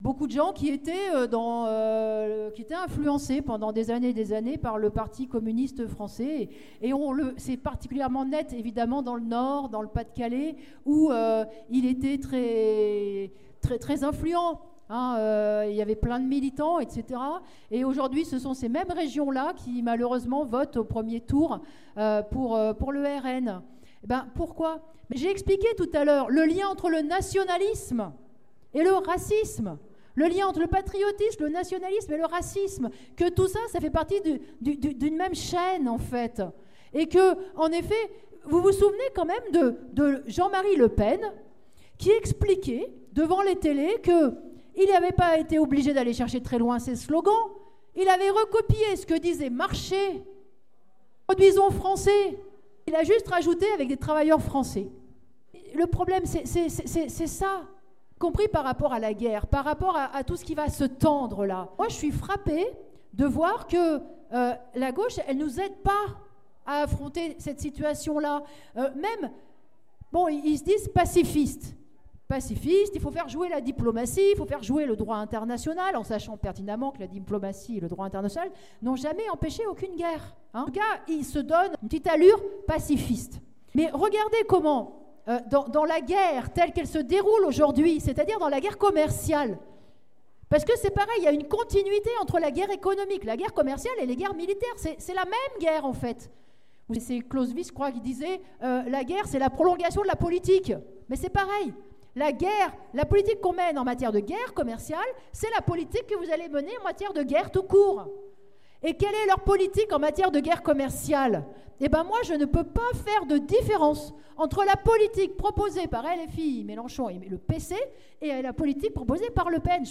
Nathalie Arthaud débat à la fête lyonnaise de LO : De LFI au RN : nationalisme et protectionisme bourgeois